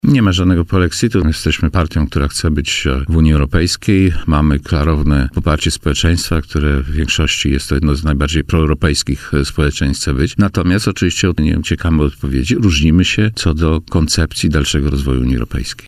Jednocześnie minister Waszczykowski zaprzeczył, jakoby polski rząd planował ruchy zmierzające w kierunku wyjścia z Unii Europejskiej.